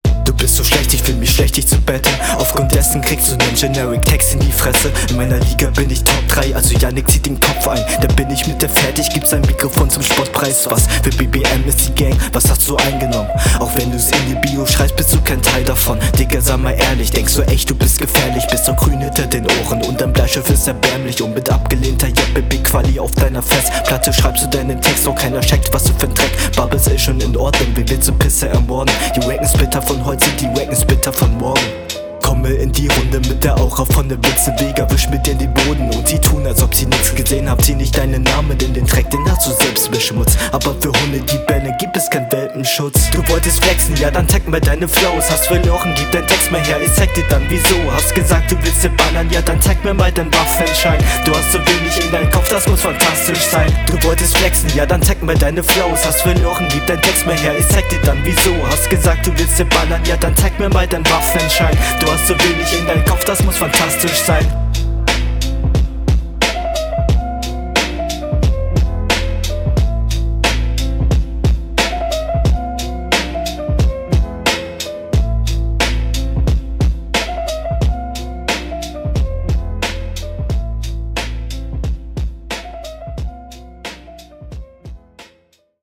Nur hast du immer noch das Problem mit den zischenden s-Lauten im Mix.